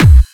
VEC3 Clubby Kicks
VEC3 Bassdrums Clubby 026.wav